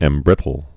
(ĕm-brĭtl)